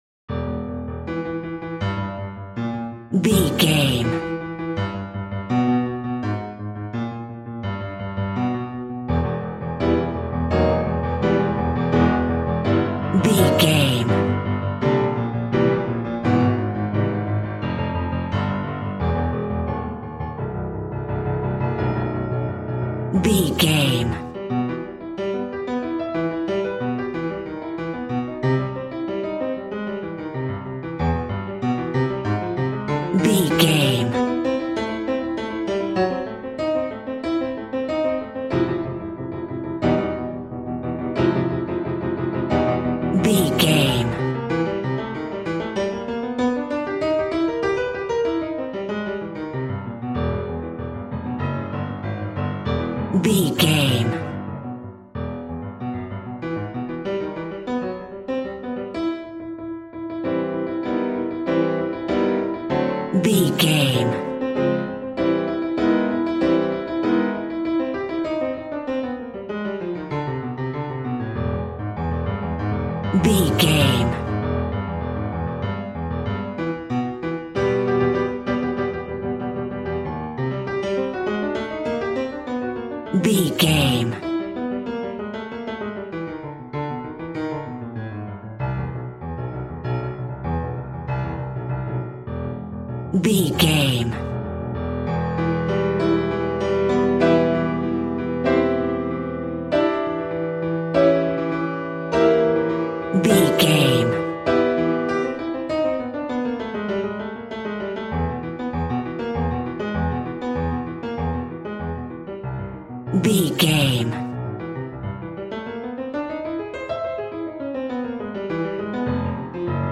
Scary Funeral.
Aeolian/Minor
Fast
ominous
haunting
eerie
piano
horror music
Horror Pads
horror piano
Horror Synths